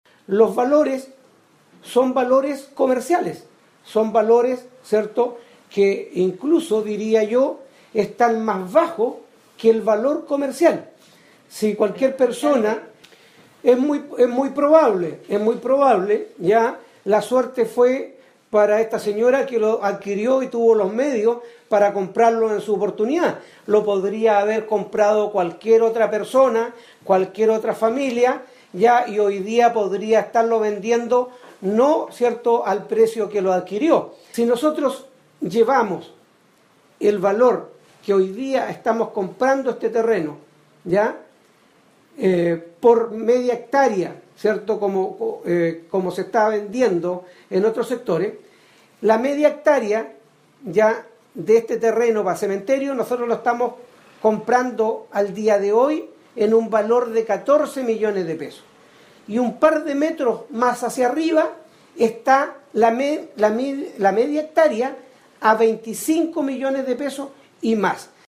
Gómez defendió la forma en que el municipio adquirió el terreno y señaló que los valores están a su juicio, por debajo de los precios actuales en que están otros terrenos en estos puntos de la comuna.